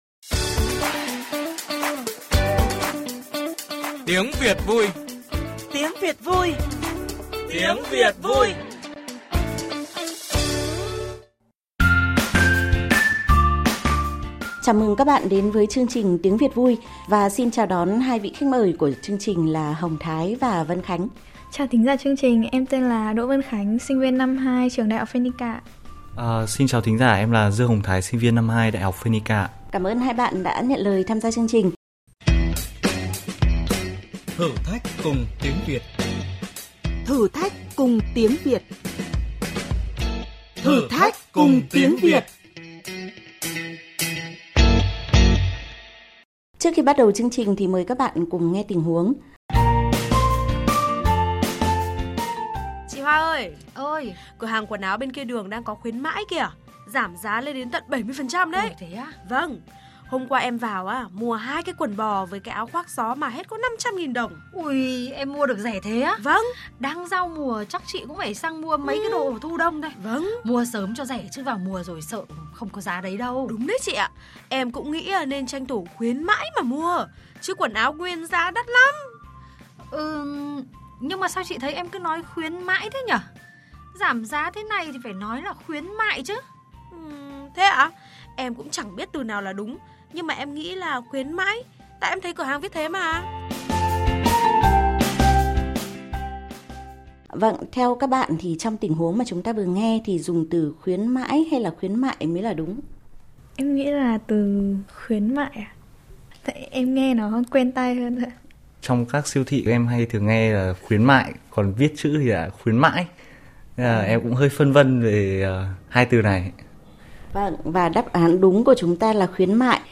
[VOV2] Thứ sáu, 10:39, 03/10/2025 – Chương trình Tiếng Việt vui trên VOV2, các bạn trẻ thử thách chọn từ đúng: khuyến mãi hay khuyến mại, vãn cảnh chùa hay vãng cảnh chùa…